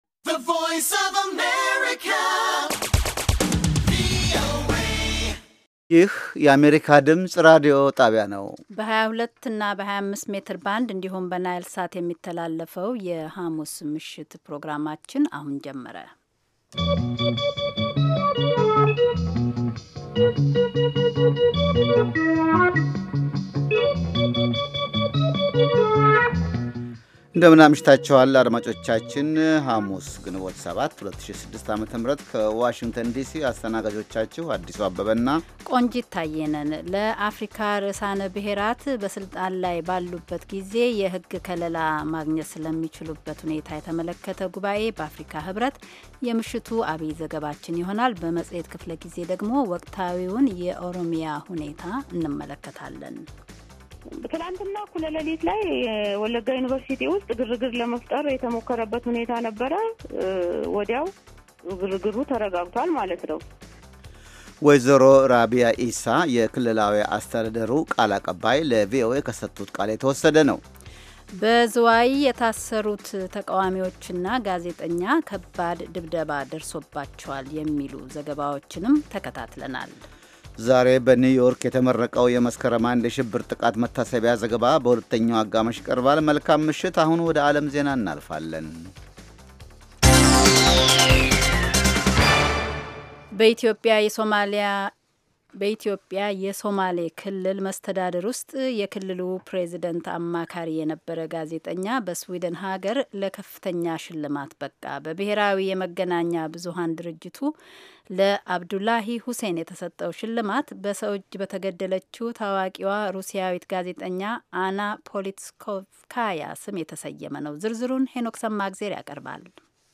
ዘወትር ከምሽቱ ሦስት ሰዓት ላይ ኢትዮጵያና ኤርትራ ውስጥ ለሚገኙ አድማጮች በአማርኛ የሚተላለፉ ዜናዎች፣ ቃለመጠይቆችና ሌሎችም ትኩስ ዘገባዎች፤ እንዲሁም በባሕል፣ በጤና፣ በሴቶች፣ በቤተሰብና በወጣቶች፣ በፖለቲካ፣ በግብርና፣ በንግድ፣ በተፈጥሮ አካባቢ፣ በሣይንስ፣ በቴክኖሎጂ፣ በስፖርት፣ በሌሎችም አካባቢያዊና የመላ አፍሪካ ጉዳዮች ላይ ያተኮሩ መደበኛ ዝግጅቶች የተካተቱባቸው የአንድ ሰዓት ዕለታዊ ሥርጭቶች